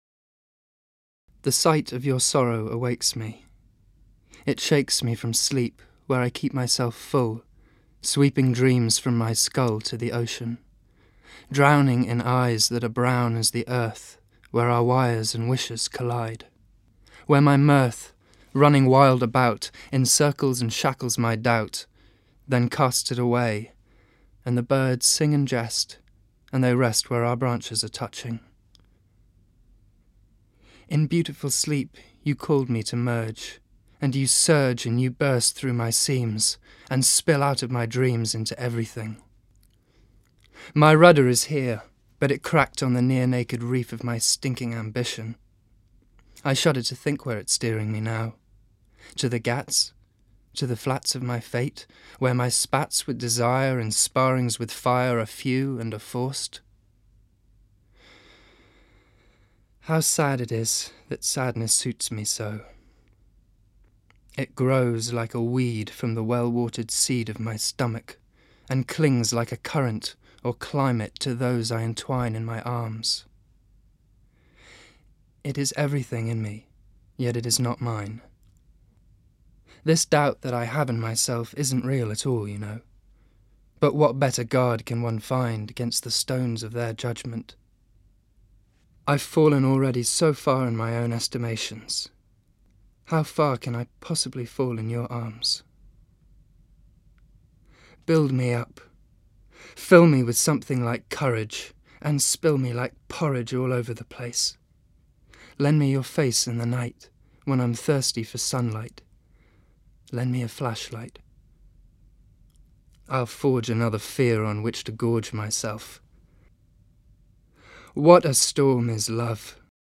POETRY READINGS
I Sat By Myself (page 33) read by Honeysuckle Weeks